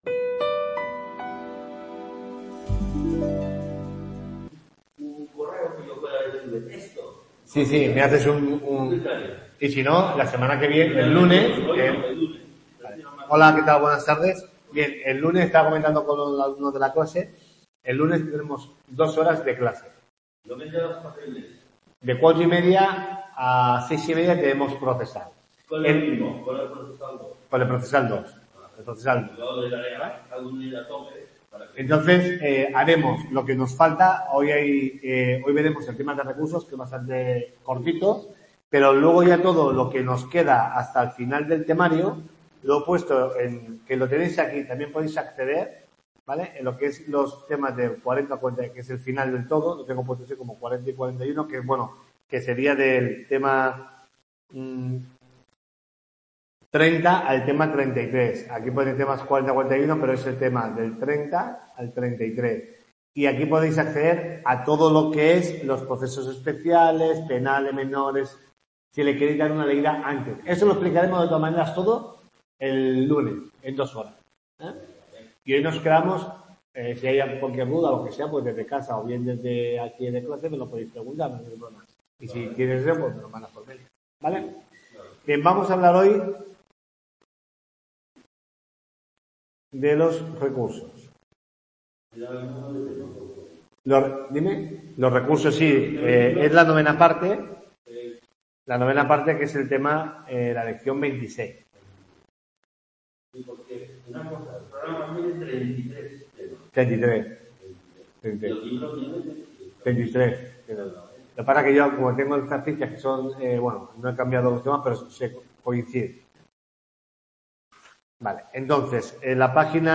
TUTORIA 10